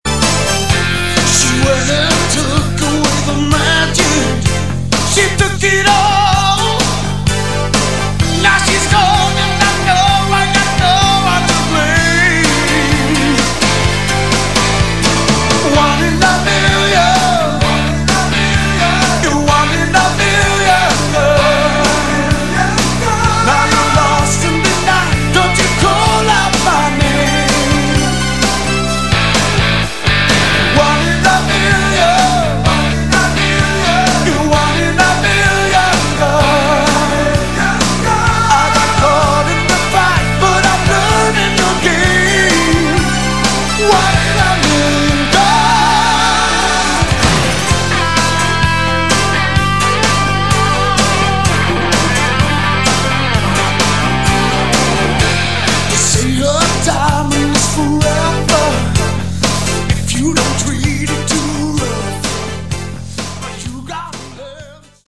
Category: Glam/Hard Rock
guitars, keyboards
vocals
drums